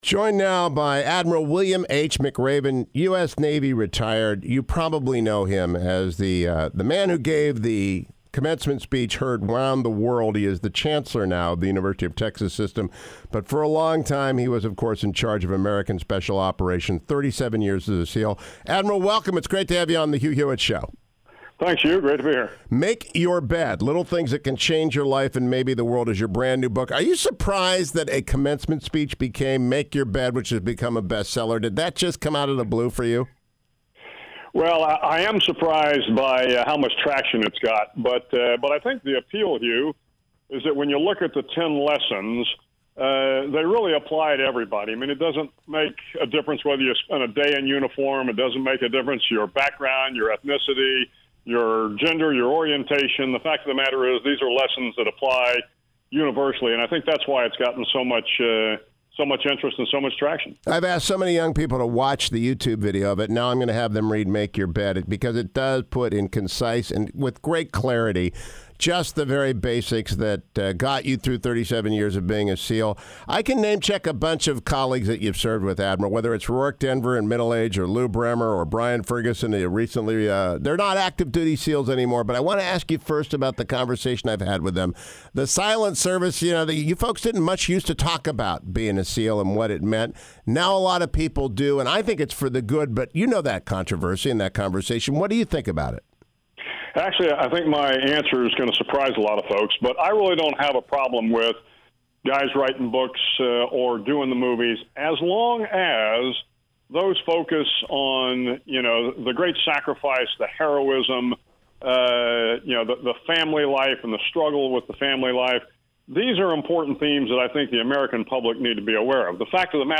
He is now the Chancellor of the University of Texas System and author of a brand new best-seller Make Your Bed, which is built off his much watched University of Texas Commencement Speech last year. He joined me this morning: